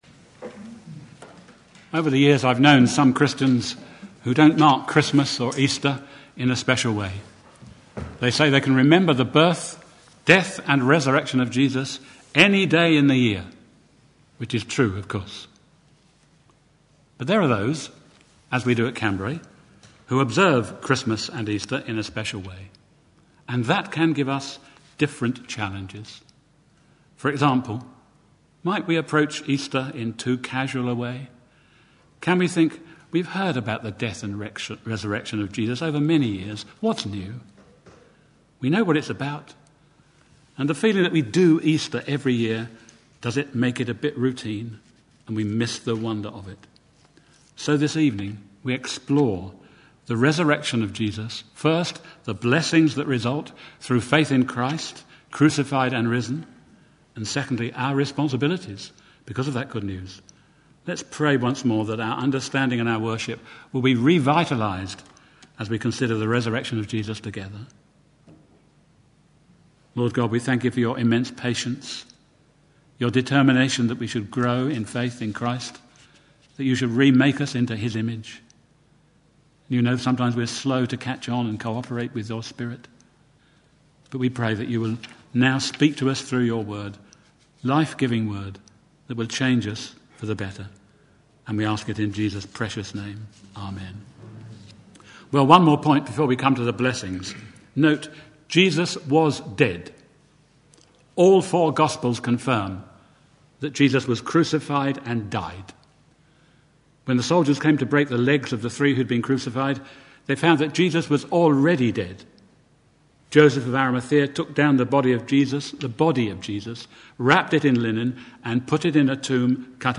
Series: Easter Day